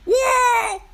The woah is a high-energy audio clip commonly used in memes, TikToks, and YouTube shorts Movies sound and meme culture.
Featuring a punchy beat with iconic "woah", it's a staple in modern Movies sound and meme culture.
woah